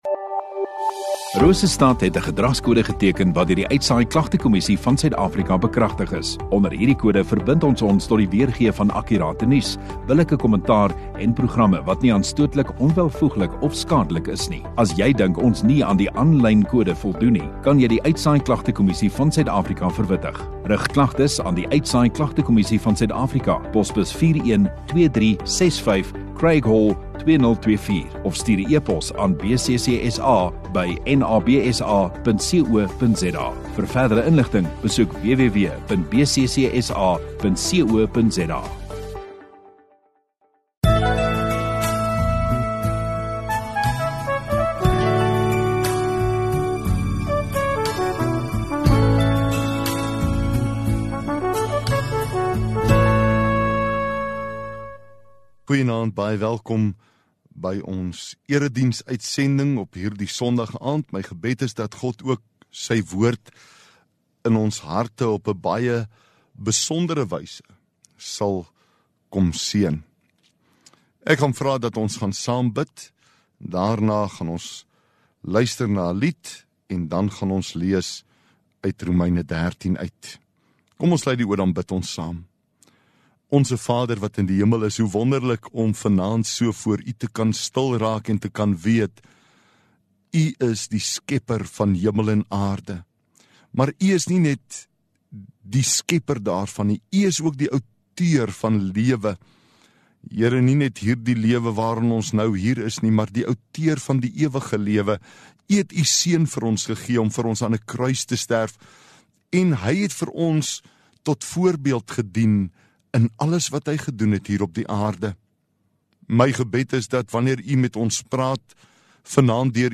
1 Sep Sondagaand Erediens